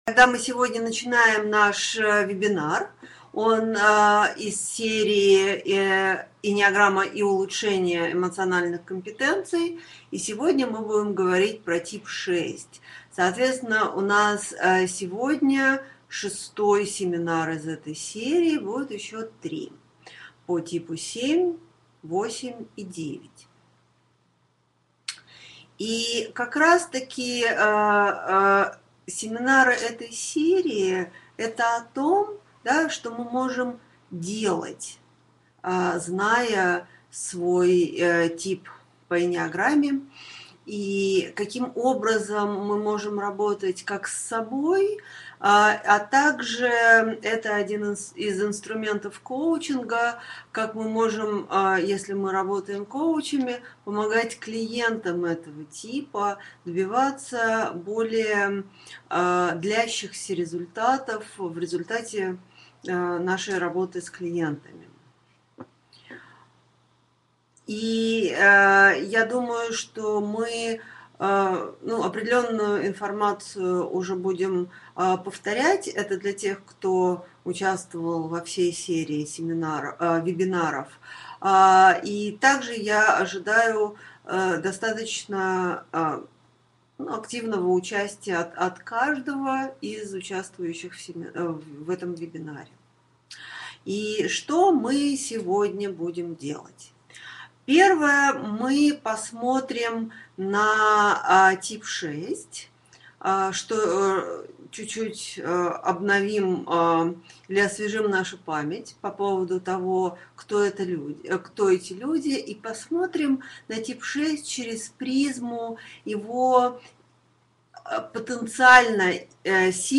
Аудиокнига Осознанность в действии. Тип 6 | Библиотека аудиокниг